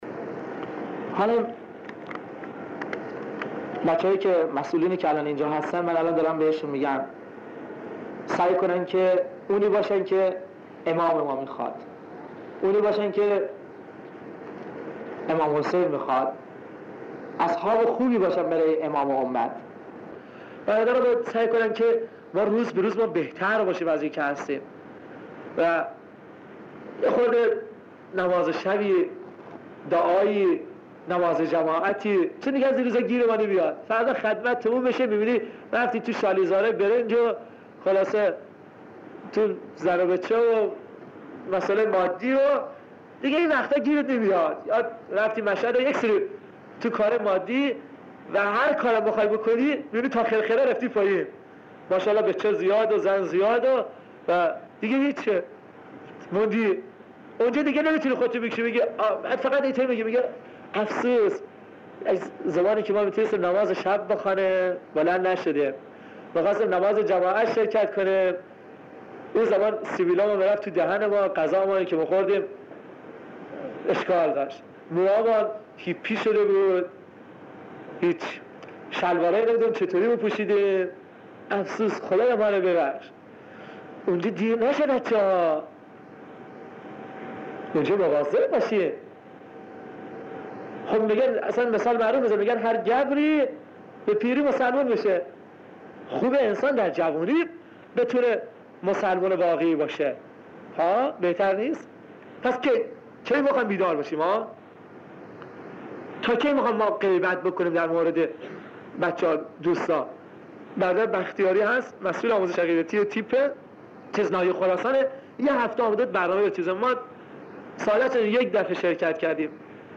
برای اسلام شهید شد+ صوت